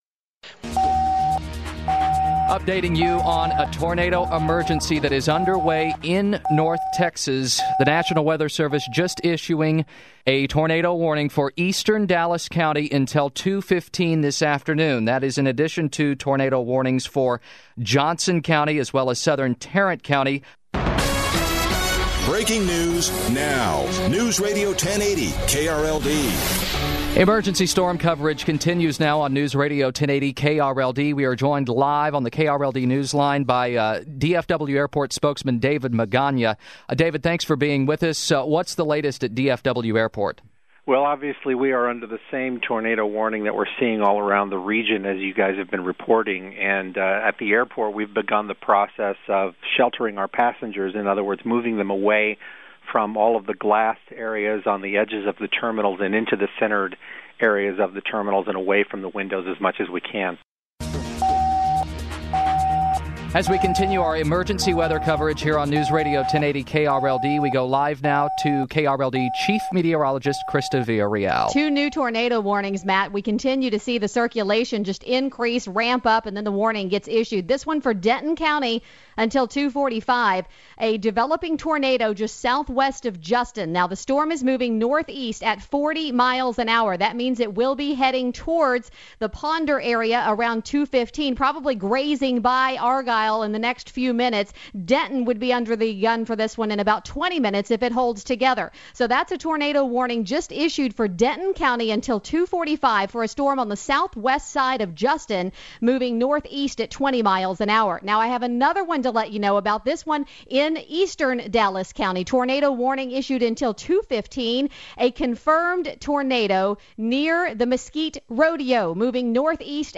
Spot News- April Tornadoes